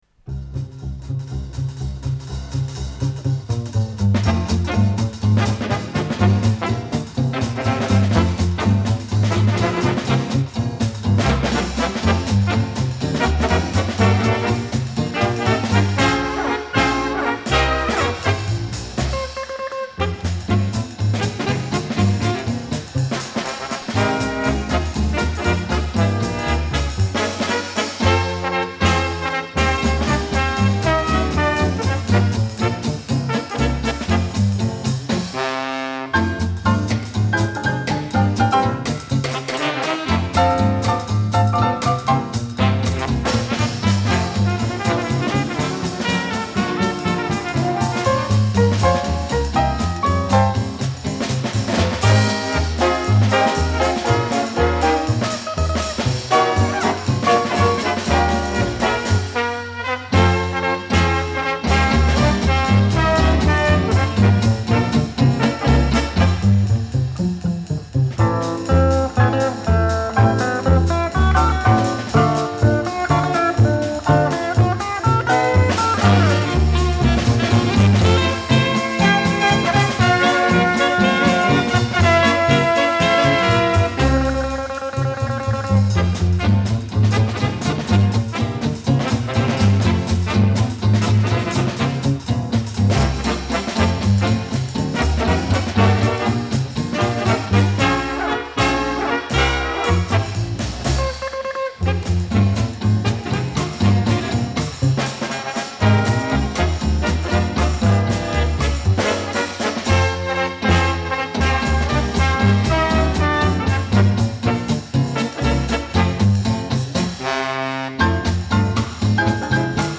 Соло на саксофоне
Инстр. ансамбль в составе
труба
тромбон
гитара
ударные
рояль